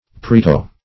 Search Result for " parieto-" : The Collaborative International Dictionary of English v.0.48: Parieto- \Pa*ri"e*to-\ (Anat.)